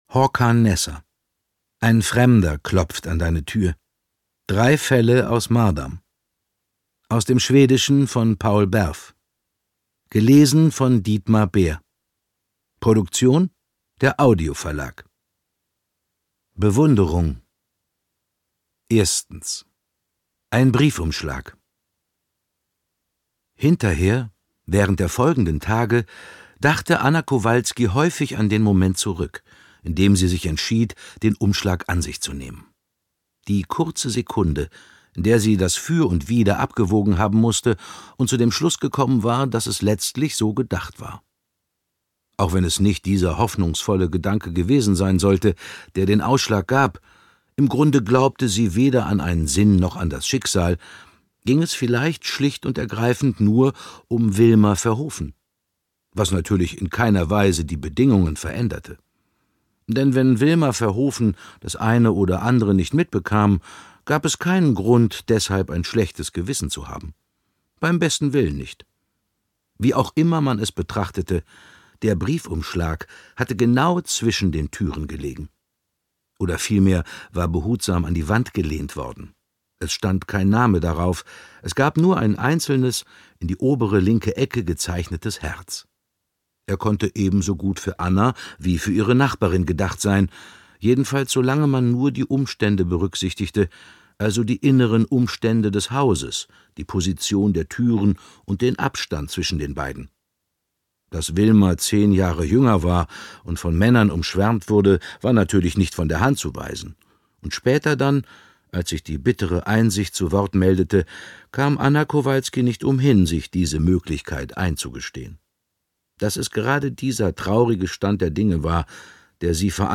Ungekürzte Lesung mit Dietmar Bär (1 mp3-CD)
Dietmar Bär (Sprecher)